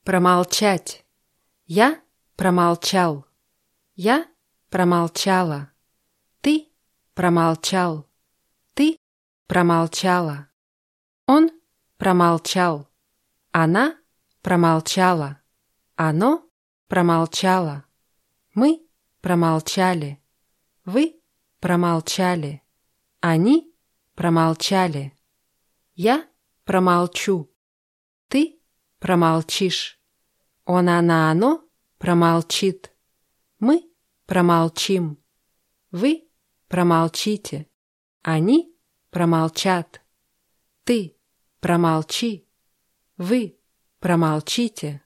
промолчать [pramaltschátʲ]